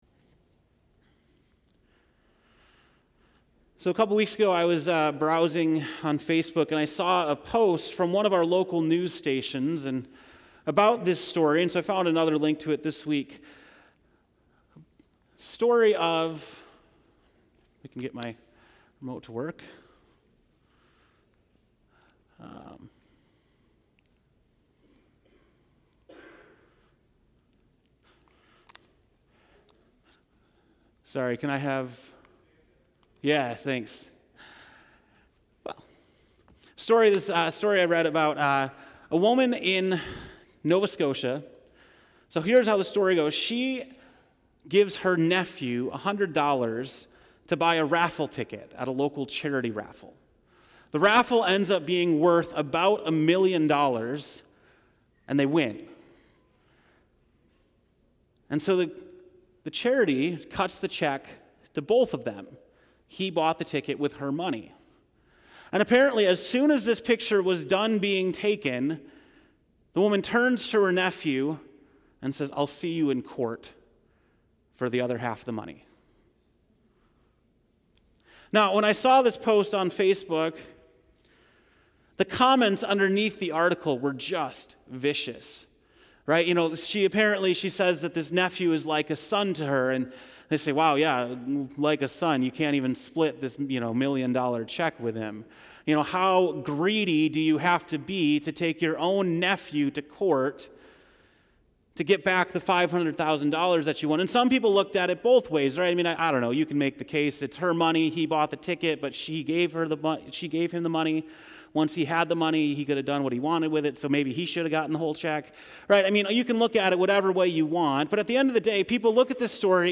July 22, 2018 (Morning Worship)